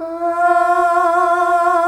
AAAAH   F.wav